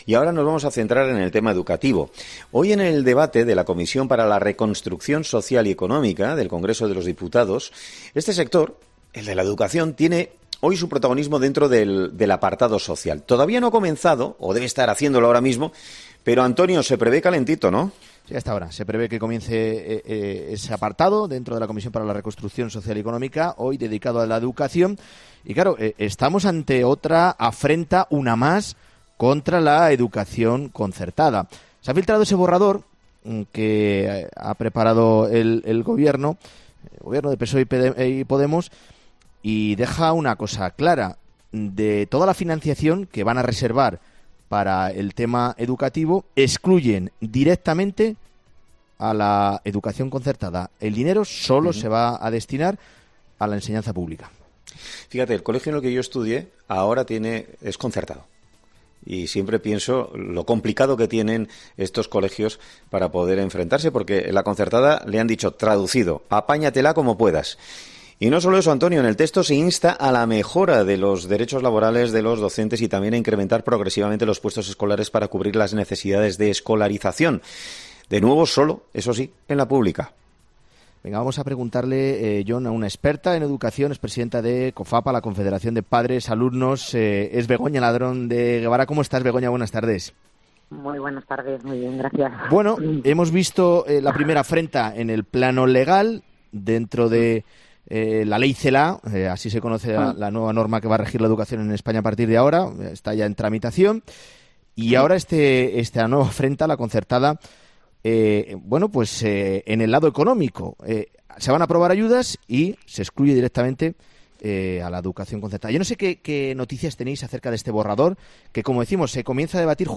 ha sido entrevistada este miércoles en 'Herrera en COPE' después de conocerse el borrador de conclusiones de la Comisión para la Reconstrucción social y Económica